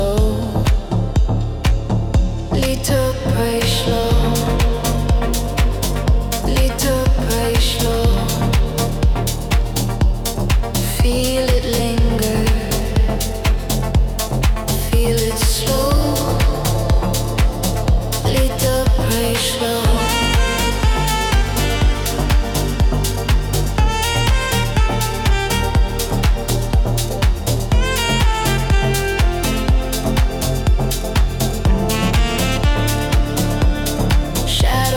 House Dance
Жанр: Танцевальные / Хаус / Украинские